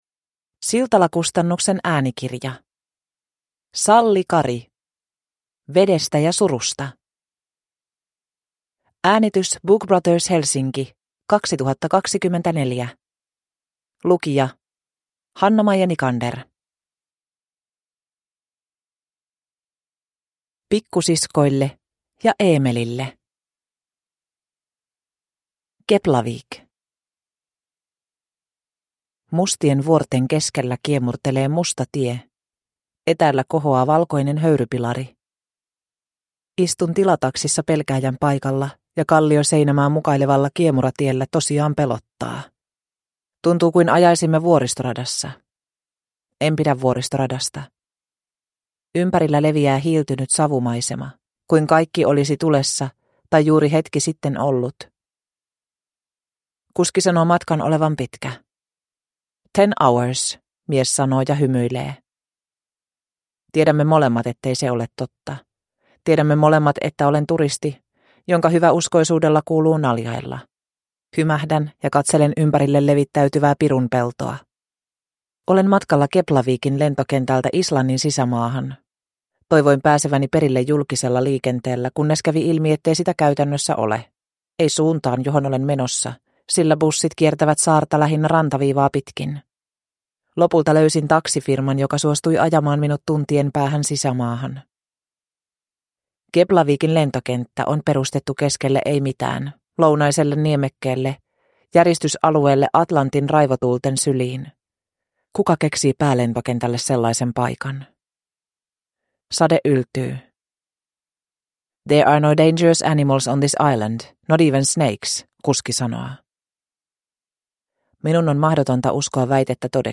Vedestä ja surusta (ljudbok) av Salli Kari